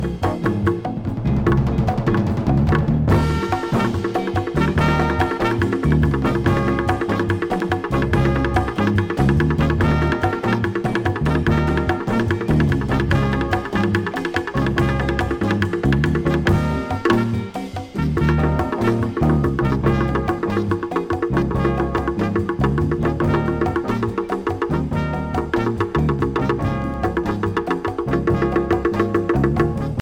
jazz drummer
Funk Jazz